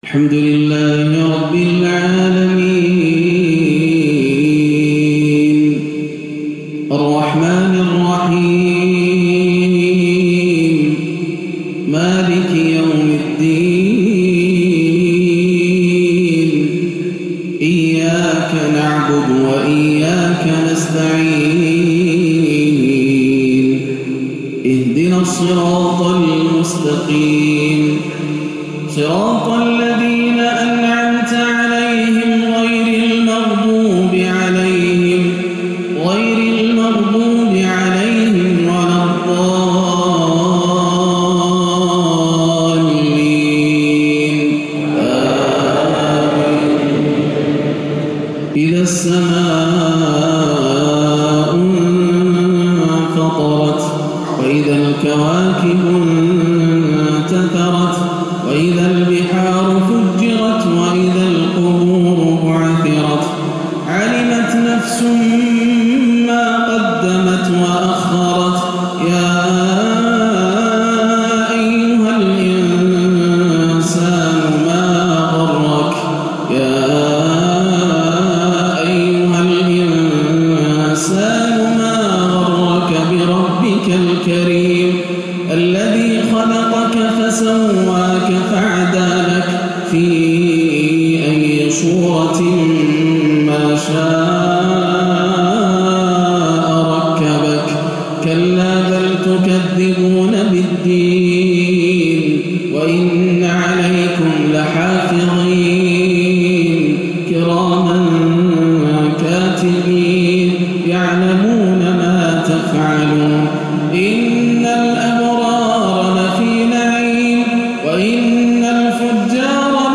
صلاة الجمعة 4-4-1439هـ سورتي الإنفطار و الضحى > عام 1439 > الفروض - تلاوات ياسر الدوسري